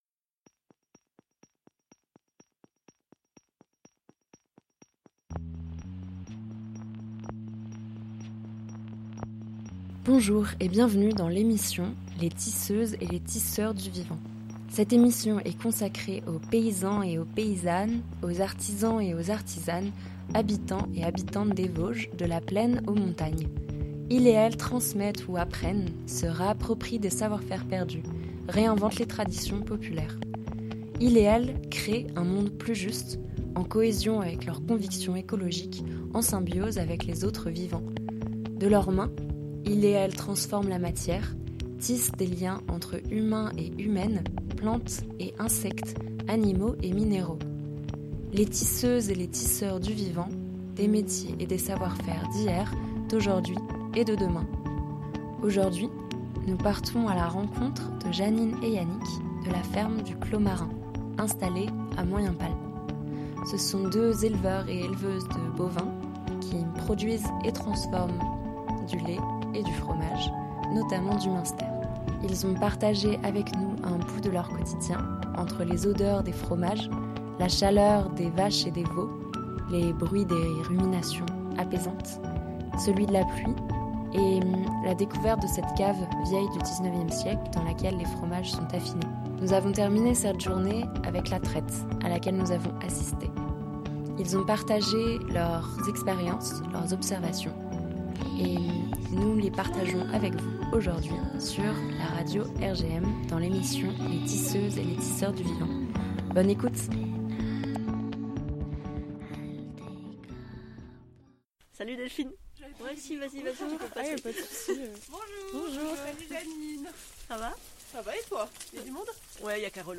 Entre les odeurs de fromages, la chaleur des vaches et des veaux, le bruit apaisant des ruminations et de la pluie, la cave d'affinage et la traite. Ils nous ont partagé leurs expériences et leurs savoir-faire en élevage bovin et en production de fromages, notamment le munster AOP.